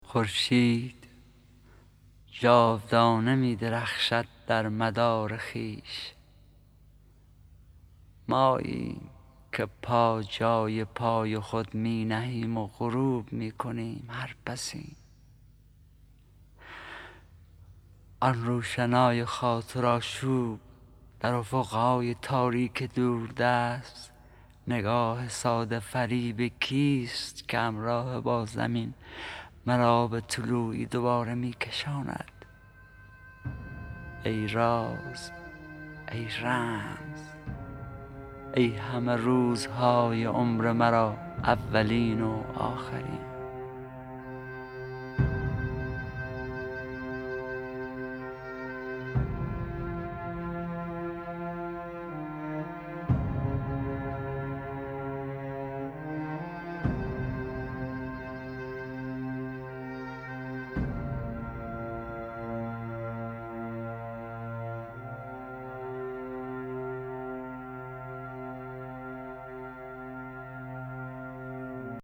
دانلود دکلمه خورشید با صدای حسین پناهی
گوینده :   [حسین پناهی]